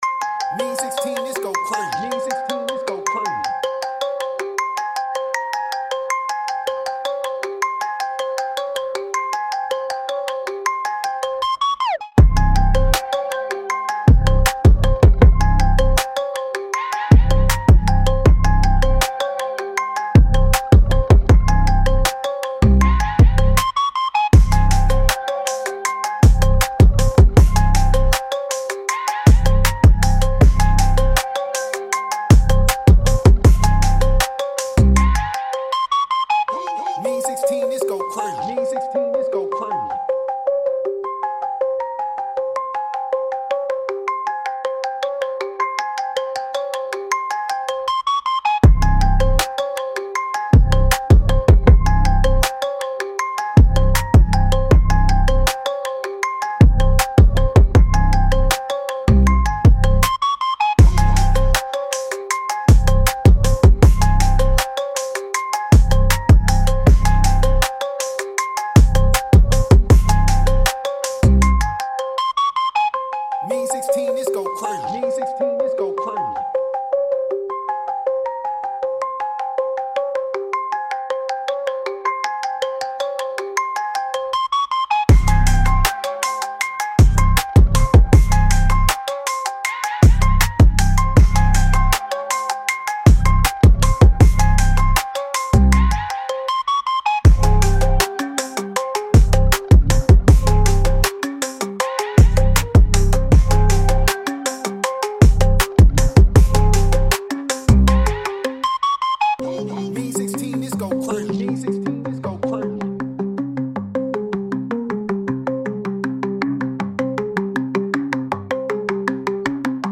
HARD TRAP BEAT
C-Min 158-BPM